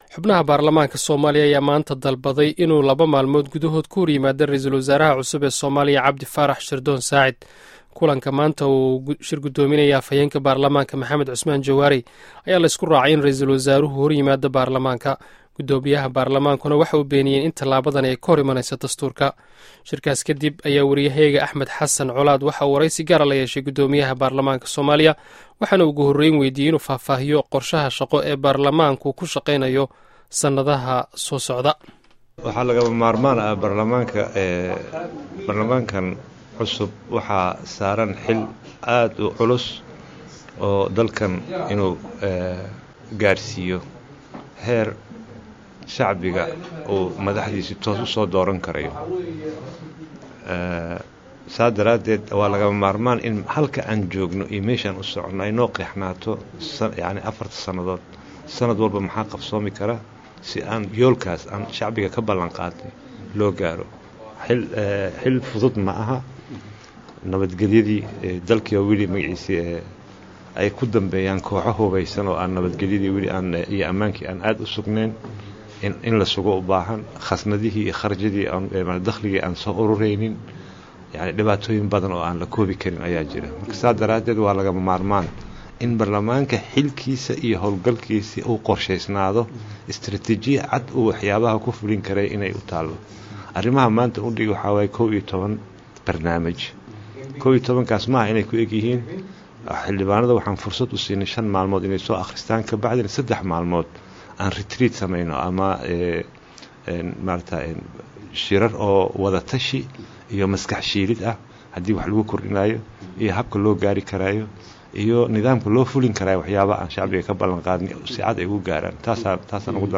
Wareysiga M. C. Jawaari